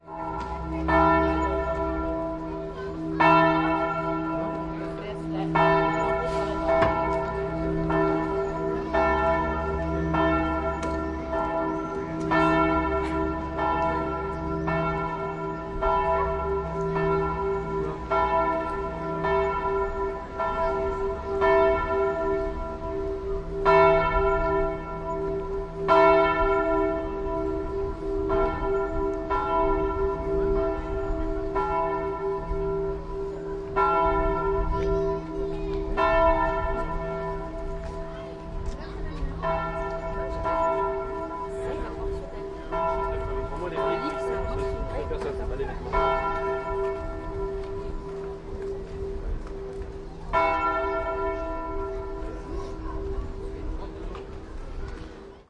描述：教堂的钟声在卢尔德。我在卢尔德（法国西南部）录制了这个文件，这是一个天主教圣地。你可以听到教堂的钟声，人们在说话，有些鸟儿，以及远离城市的交通。 2015年7月录制奥林巴斯LS100（内置麦克风）。在Audacity中应用80Hz 6dB / oct的高通滤波器。
Tag: 铃铛 卢尔德 教堂的钟声 教堂 环境 法国 铃声 钟声 现场录音